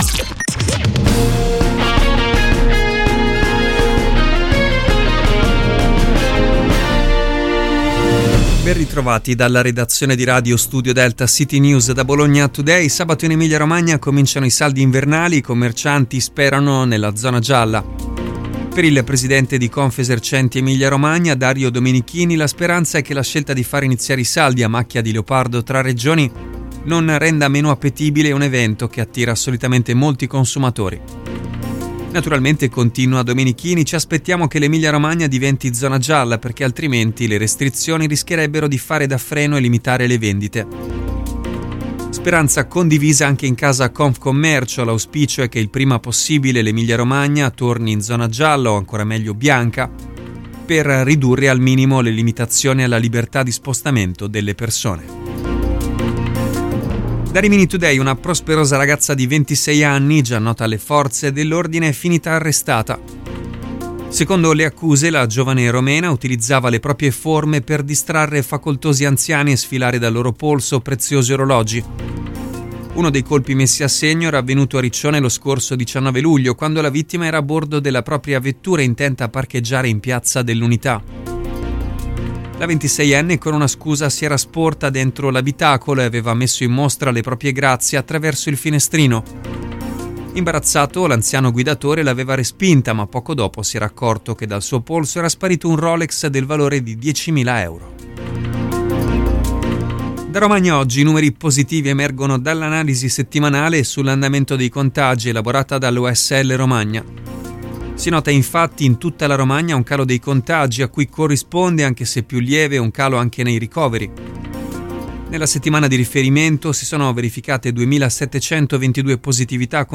Ascolta le notizie locali di Radio Studio Delta in collaborazione con CityNews